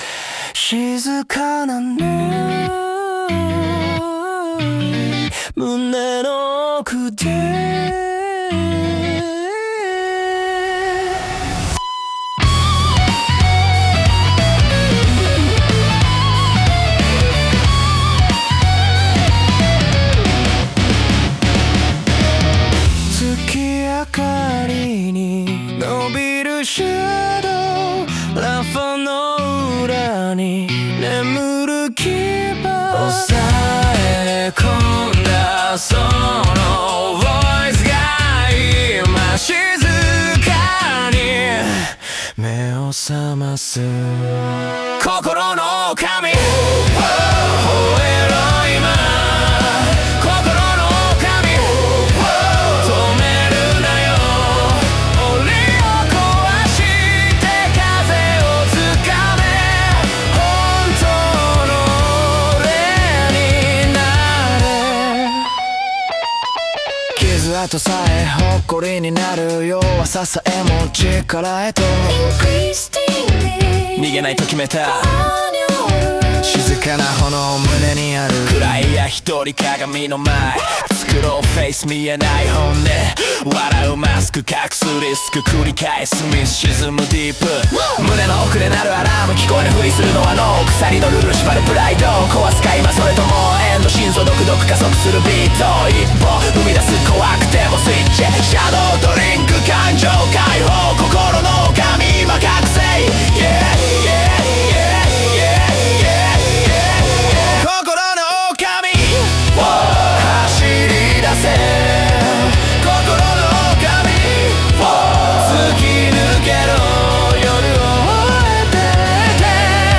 男性ボーカル
イメージ：メロディックハードコア,男性ボーカル,ダーク,ヘビー,シネマティック,アンセミック